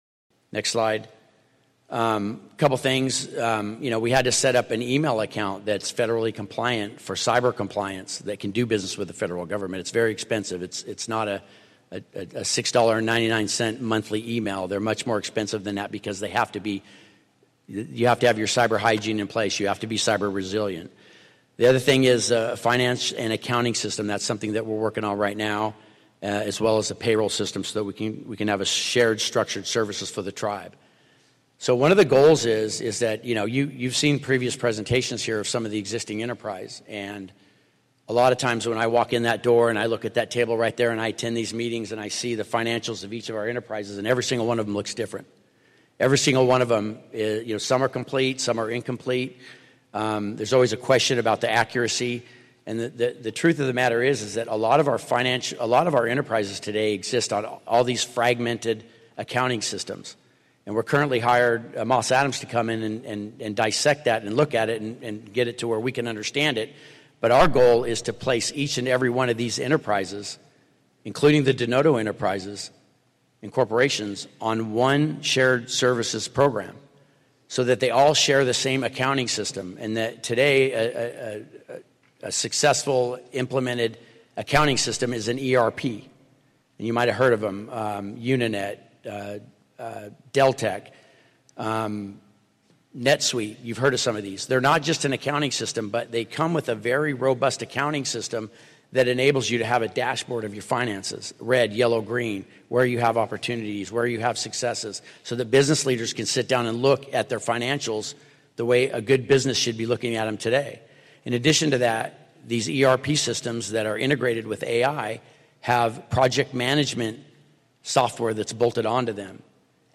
He comments on the need to better organize and unify the overall tribal fiscal systems, he talks about federal funding opportunities that may become available if we had better systems. He addressed some audience questions. Tribal members present their thoughts on the local economy, fiscal systems and the accomplishments of tribal departments and local entrepreneurs.